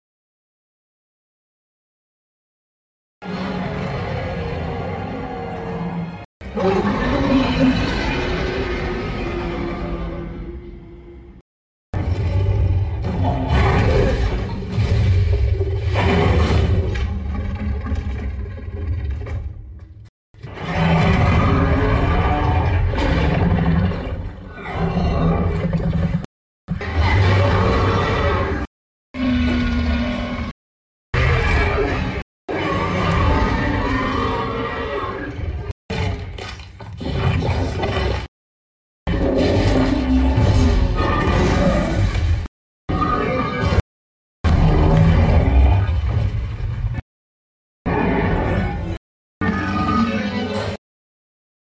D.Rex Roars and Growls (Jurassic